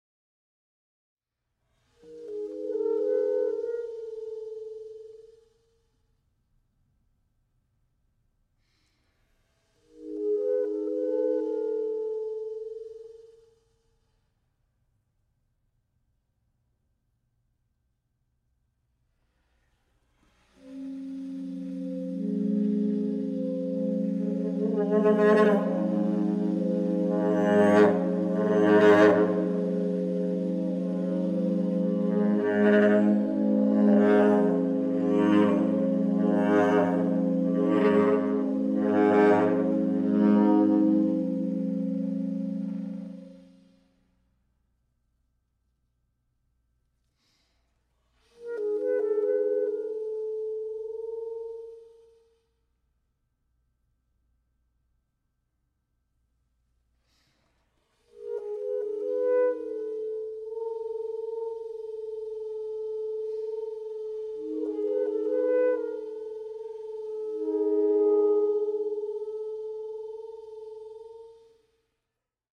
baritone saxophone